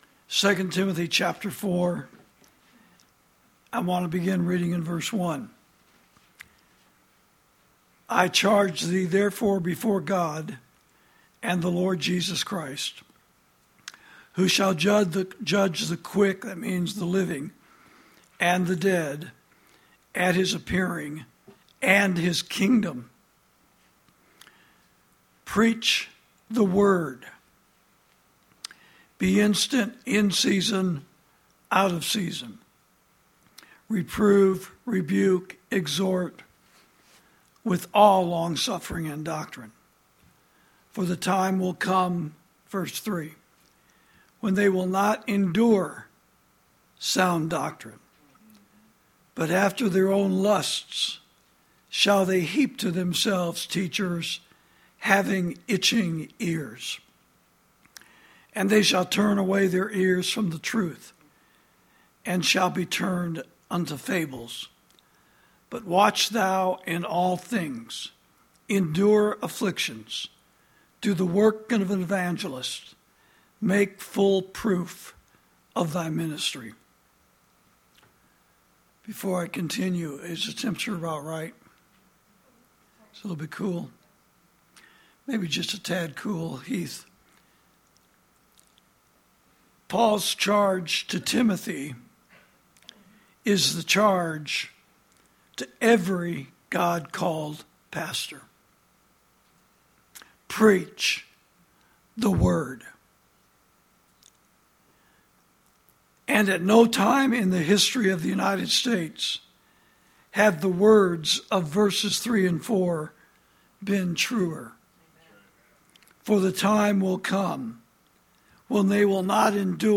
Sermons > The Loss Of Freedom Of Speech Means The Loss Of Preaching The Word